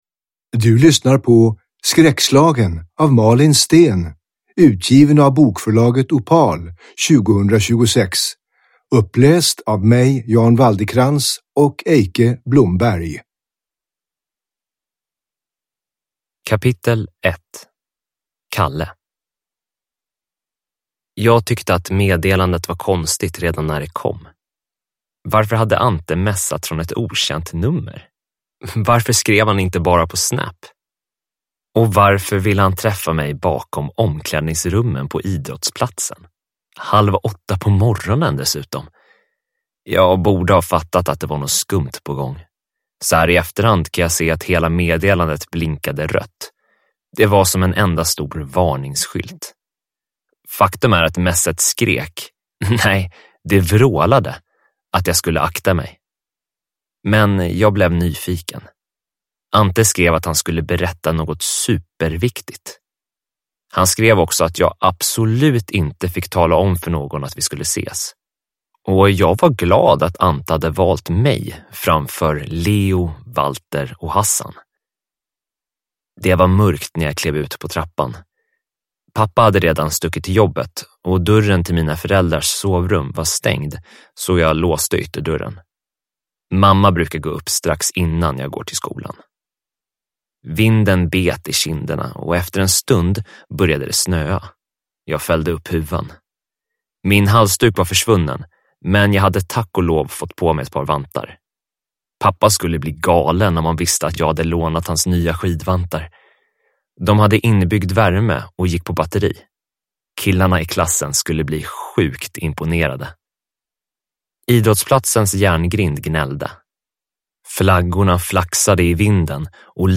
Skräckslagen – Ljudbok